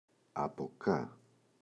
από κά [a’po ka]